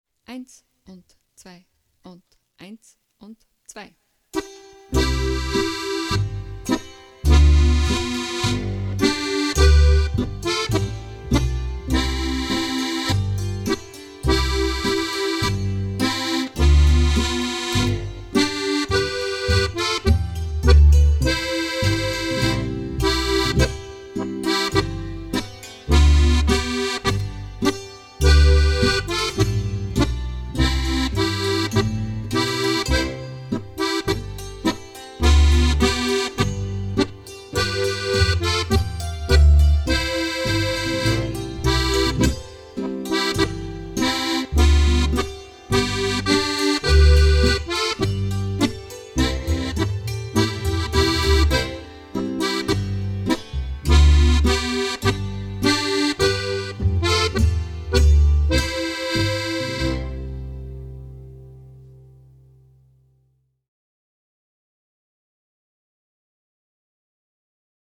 Audio-Aufnhamen zum Mitspielen und/oder Anhören
G-C-F